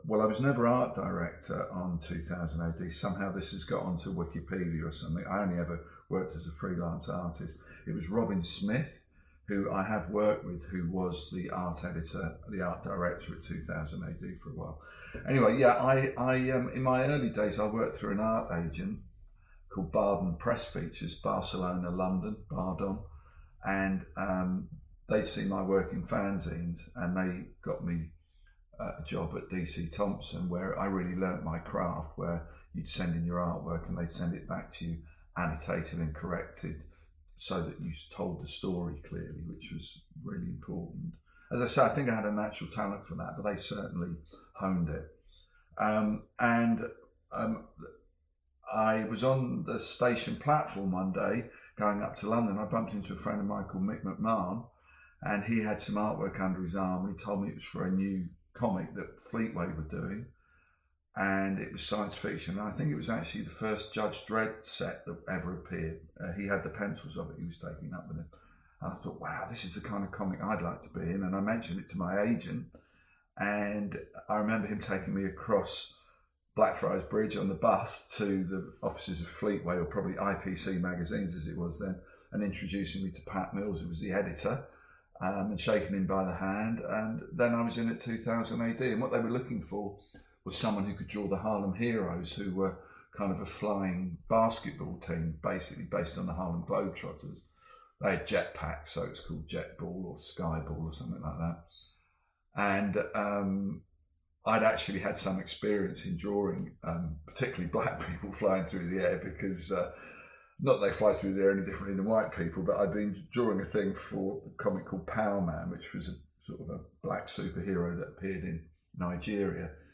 Dave Gibbons interview: How did the opportunity to work on 2000AD come about?